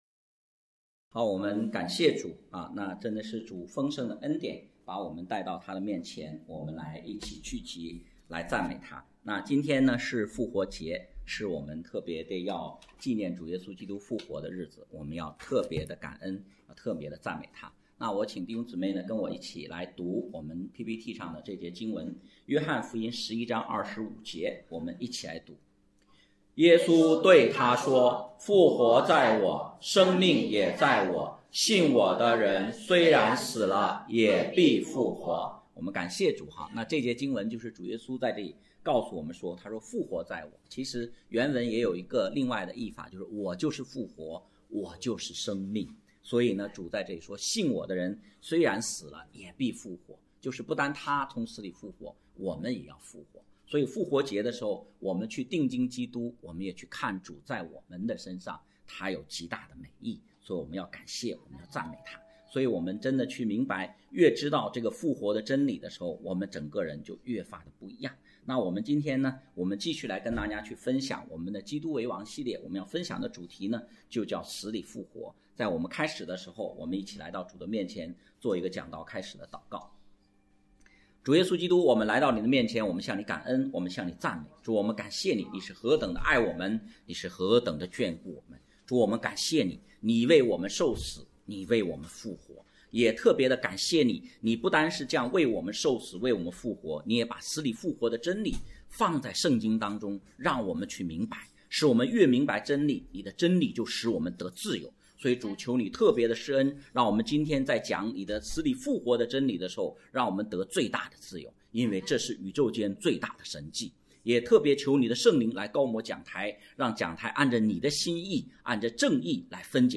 讲道录音 点击音频媒体前面的小三角“►”就可以播放： https